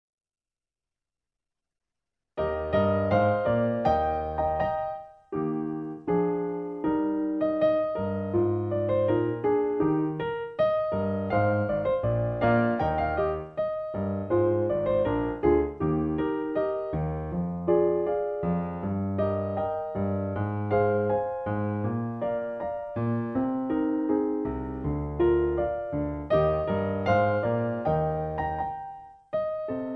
In E flat. Piano Accompaniment